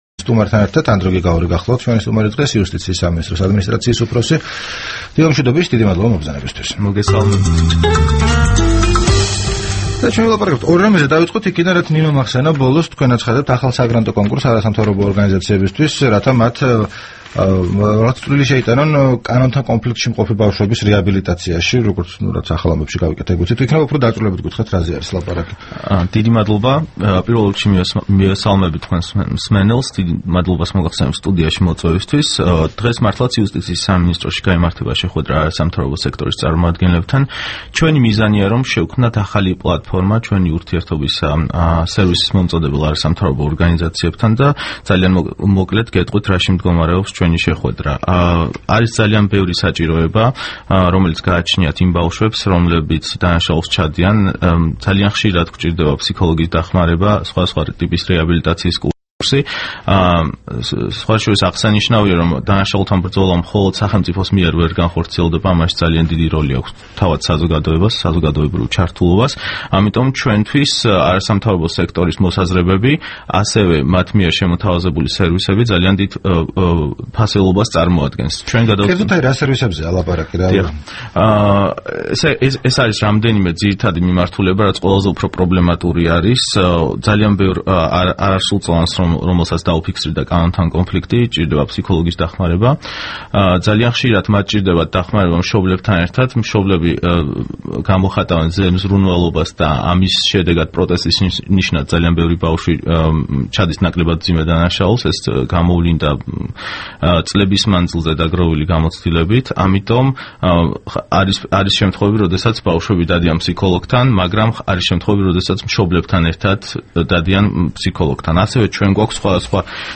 რადიო თავისუფლების თბილისის სტუდიაში სტუმრად იყო იუსტიციის სამინისტროს ადმინისტრაციის უფროსი ანდრო გიგაური.
საუბარი ანდრო გიგაურთან